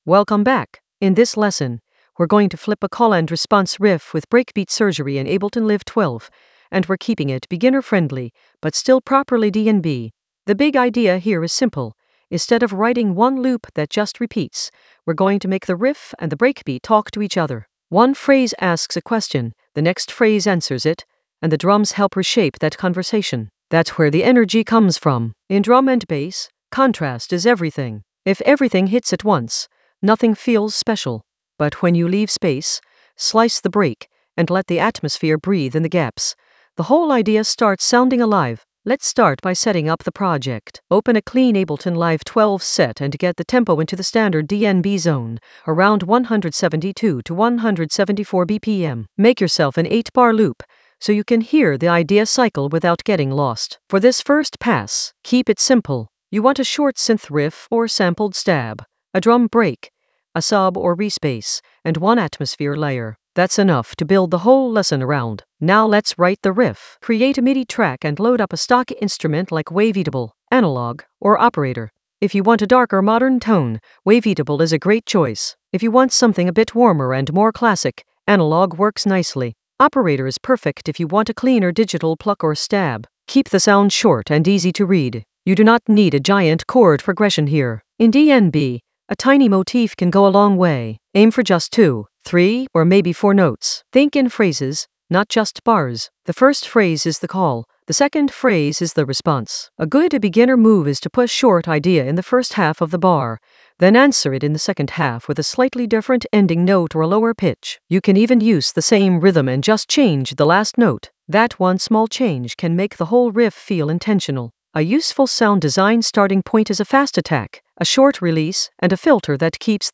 An AI-generated beginner Ableton lesson focused on Flip a call-and-response riff with breakbeat surgery in Ableton Live 12 in the Atmospheres area of drum and bass production.
Narrated lesson audio
The voice track includes the tutorial plus extra teacher commentary.